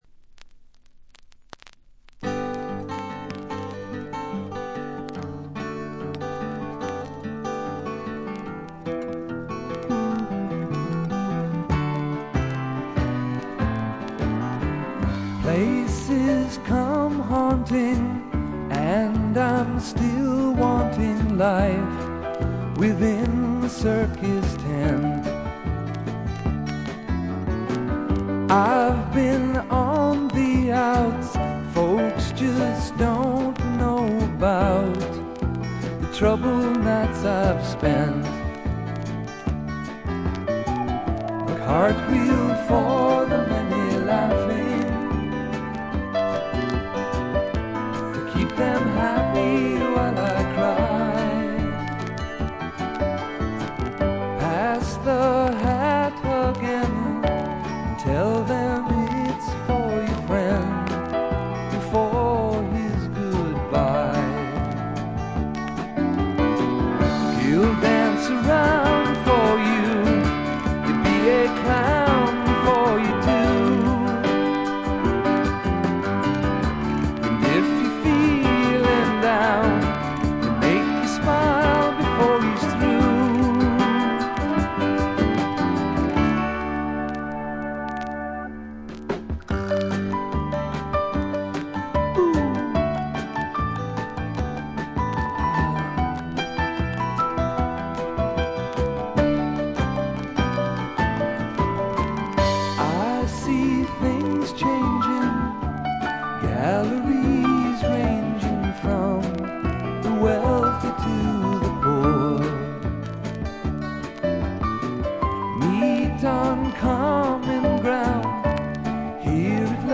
バックグラウンドノイズ、チリプチがやや多め大きめです。
試聴曲は現品からの取り込み音源です。
Vocals, Acoustic Guitar